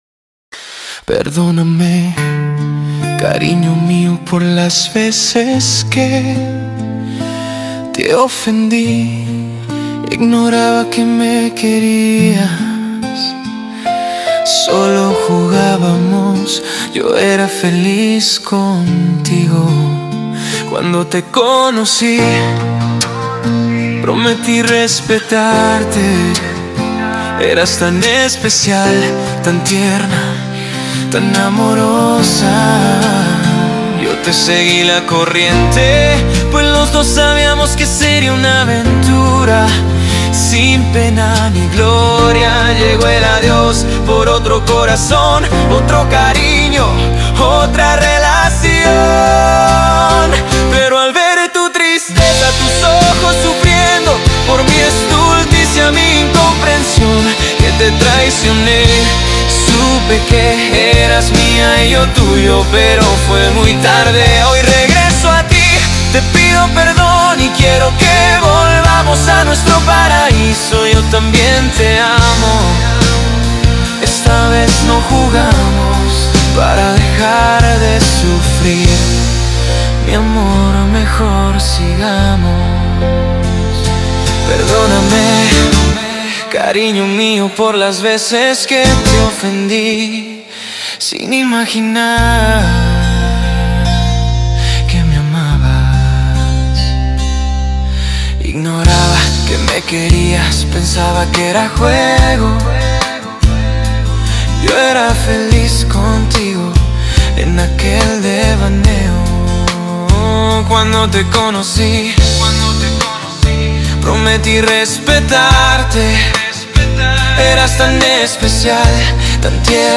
Genre Pop